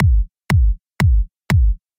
This is the sample I have selected for the low end.
You should be able to hear a solid bottom end there with a good bit of weight behind it. This sample also has a pronounced click at the top end, but this is unimportant as we will shortly be rolling off the teble using an EQ.